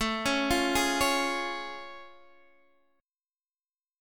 A+M7 chord